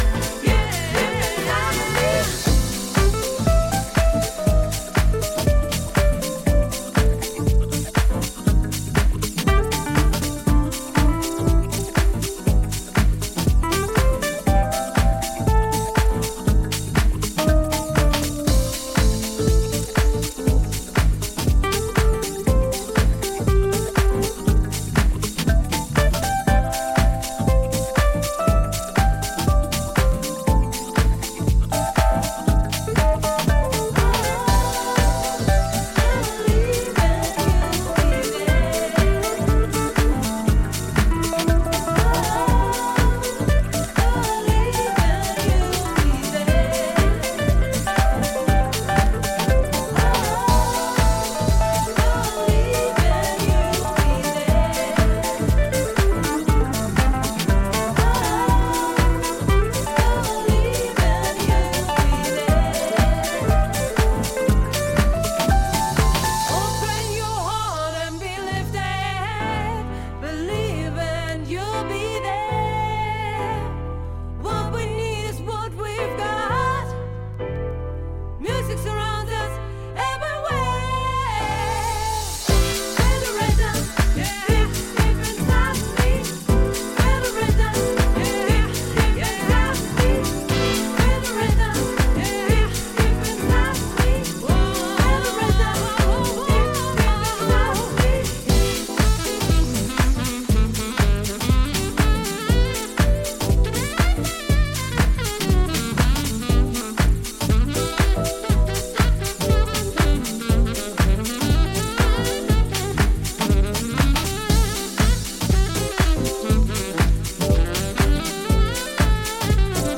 躍進の止まらない彼からエネルギーを貰える、温かなジャジー・ディープハウス集です。